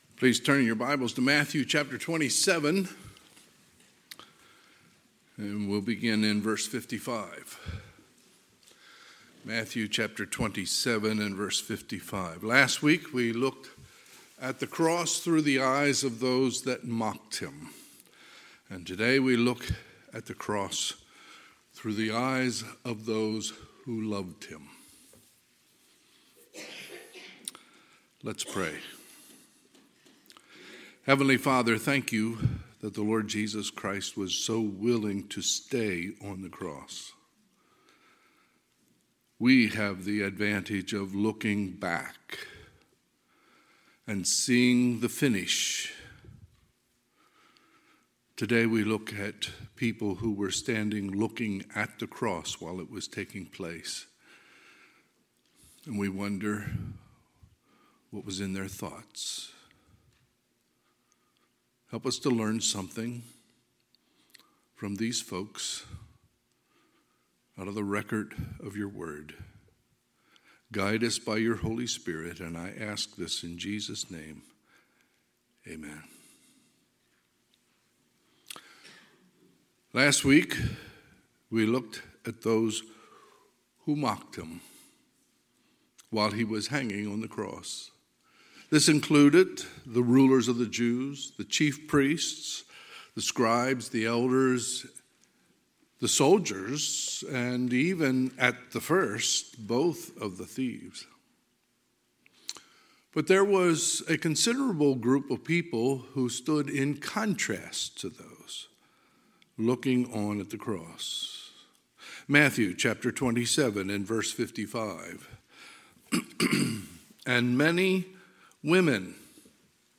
Sunday, April 10, 2022 – Sunday AM
Sermons